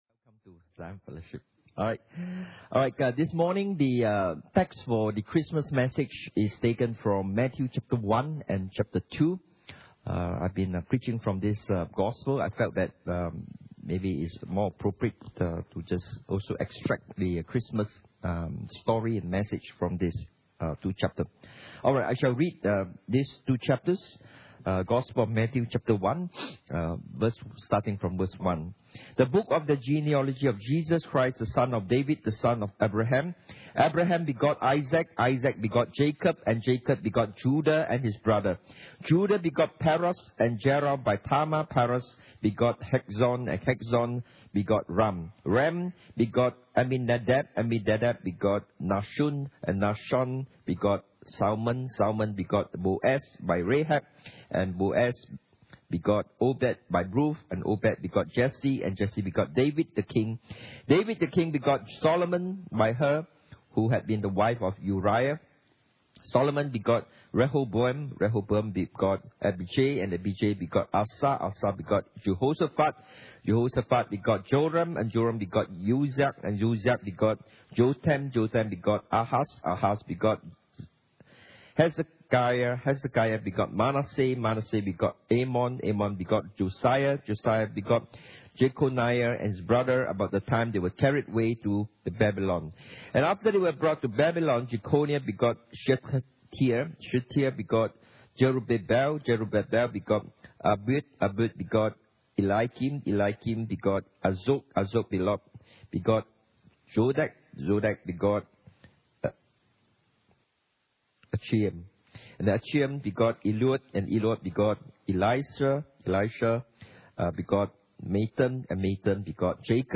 Inhouse Service Type: Sunday Morning « Jesus the Greater & Perfect Israel P3 Contentment in Christ and Life